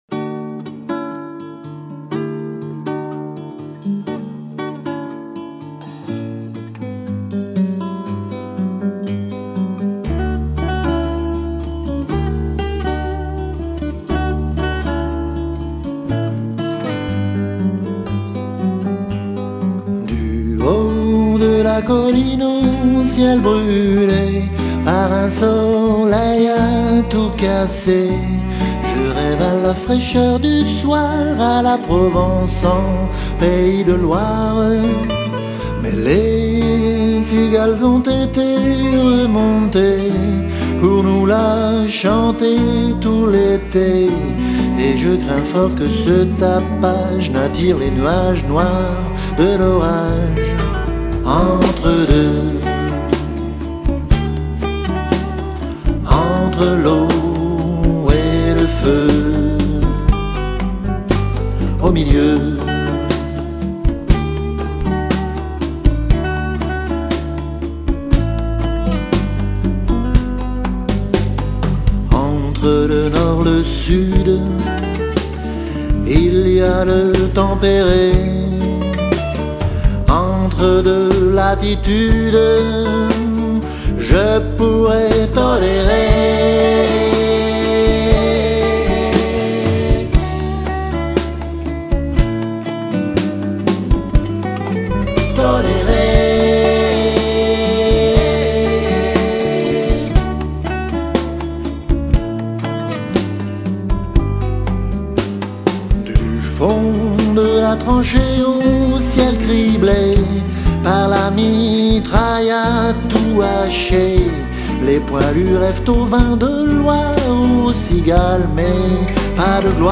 chante 13 titres originaux